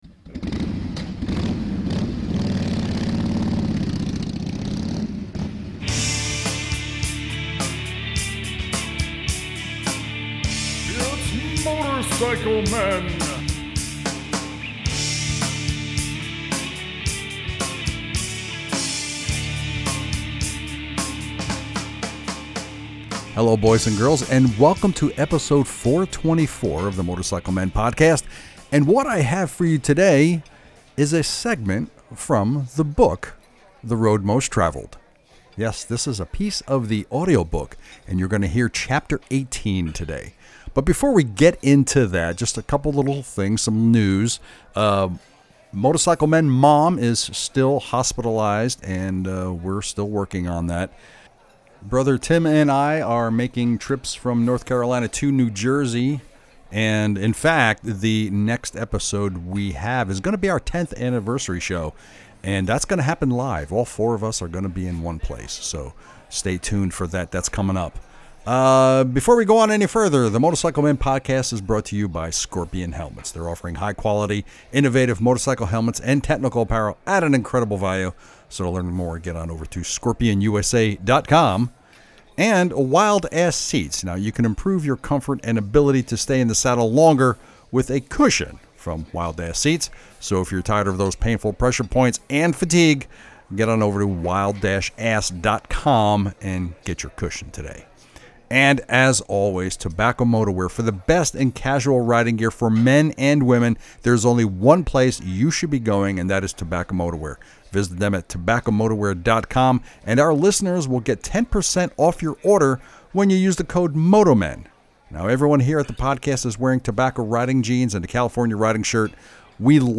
In this episode you get a portion of my audiobook for “The Road Most Traveled”. This will be Chapter 18 outlining my arrival in the Florida Keys.